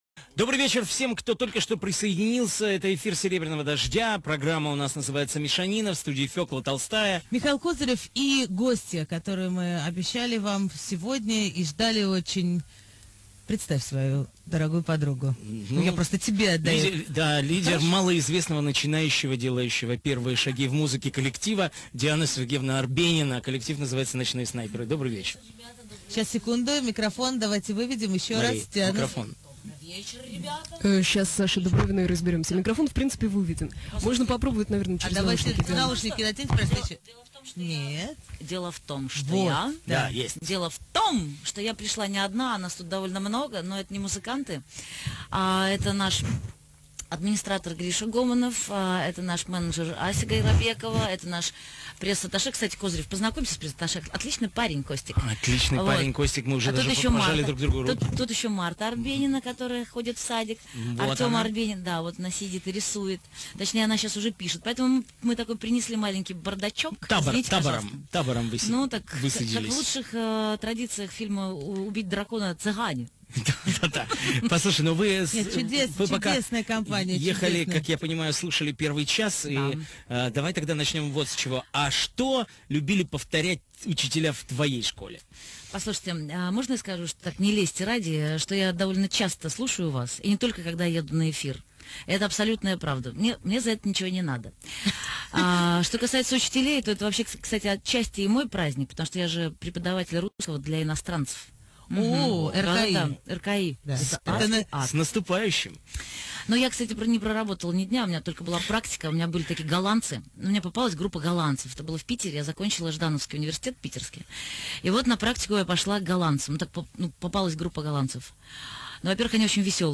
Диана Арбенина в программе «Мишанина» предыдущая следующая радио / интервью 3 октября 2014 года Диана Арбенина была гостьей программы «Мишанина» на радиостанции «Серебряный дождь».